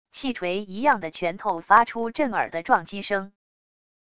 汽锤一样的拳头发出震耳的撞击声wav音频